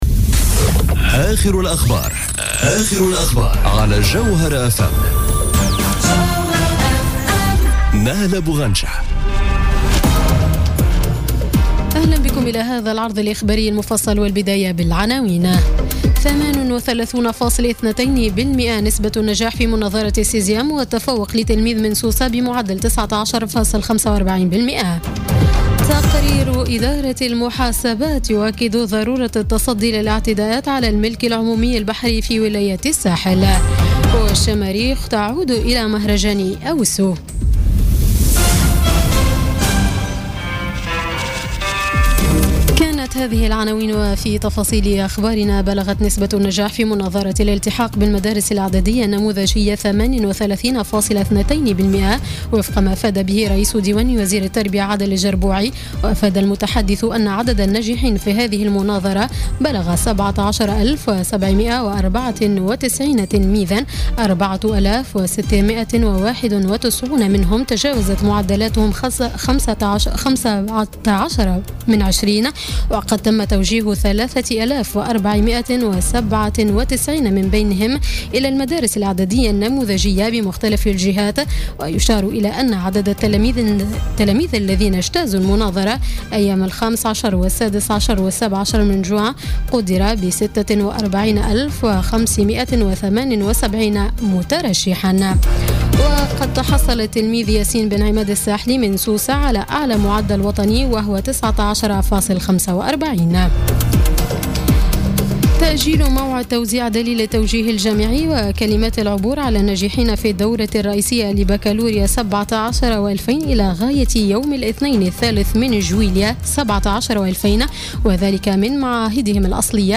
نشرة أخبار السابعة مساء ليوم الخميس 29 جوان 2017